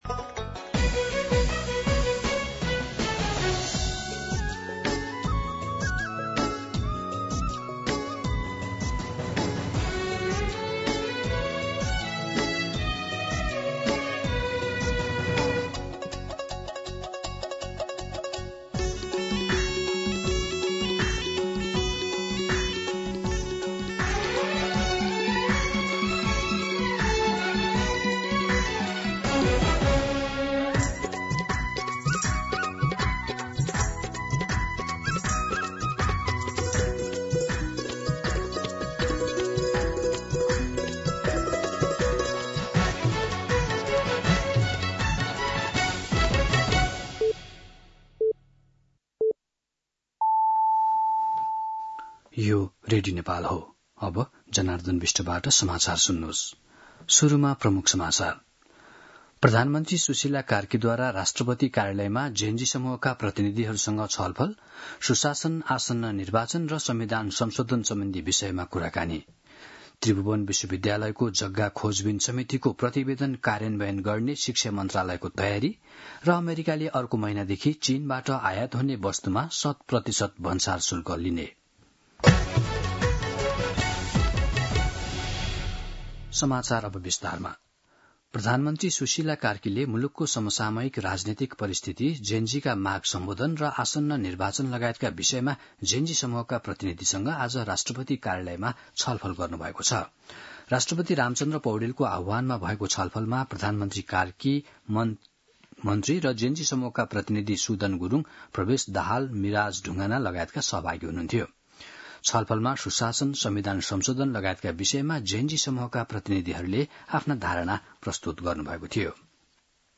दिउँसो ३ बजेको नेपाली समाचार : २५ असोज , २०८२
3-pm-Nepali-News-4.mp3